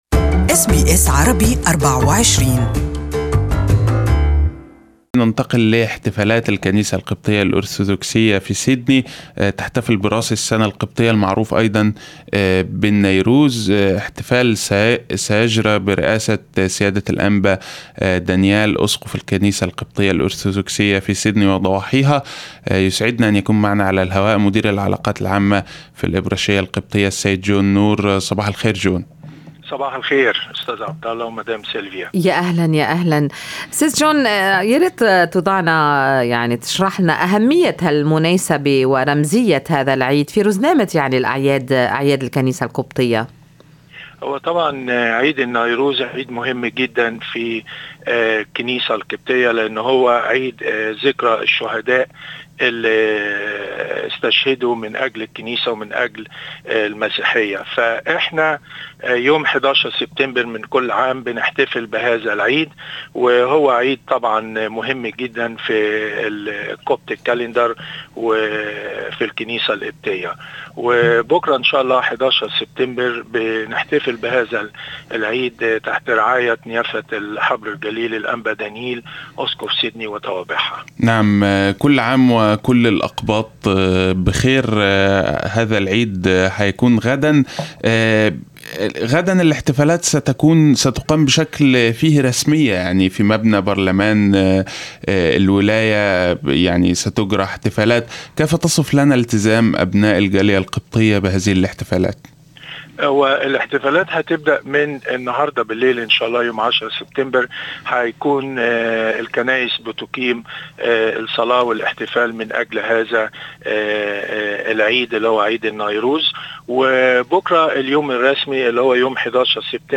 المقابلة كاملة في الرابط أعلاه استمعوا هنا الى البث المباشر لإذاعتنا و إذاعة BBC أيضا حمّل تطبيق أس بي أس الجديد على الأندرويد والآيفون للإستماع لبرامجكم المفضلة باللغة العربية.